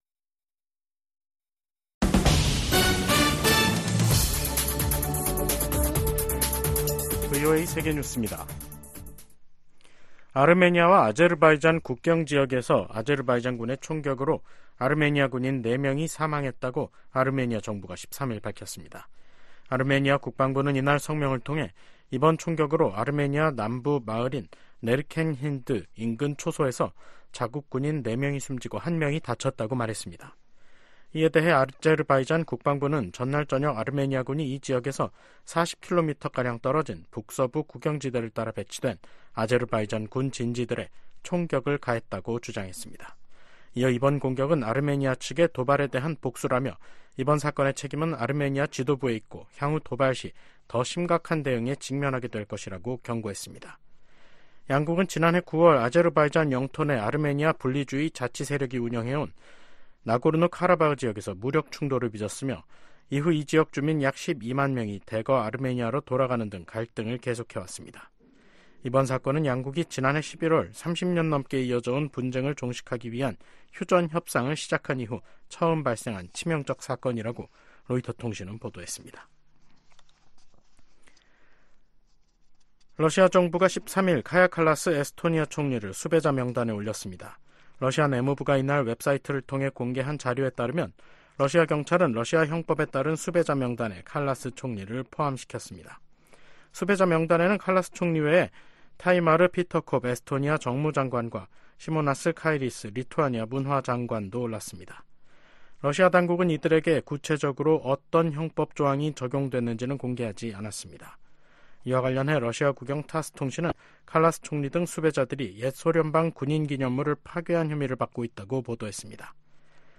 VOA 한국어 간판 뉴스 프로그램 '뉴스 투데이', 2024년 2월 13일 3부 방송입니다. 존 커비 백악관 국가안전보장회의(NSC) 전략소통조정관은 인도태평양 전략 발표 2주년을 맞아 대북 감시를 위한 한반도 주변 역량 강화가 큰 성과라고 평가했습니다. 러시아가 북한의 7차 핵실험 가능성 등을 언급하며 북한 입장을 두둔하고 있습니다. 로버트 켑키 미 국무부 부차관보는 미국·한국·일본이 북한-러시아 군사협력 대응 공조에 전념하고 있다고 밝혔습니다.